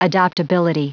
Prononciation du mot adoptability en anglais (fichier audio)
Prononciation du mot : adoptability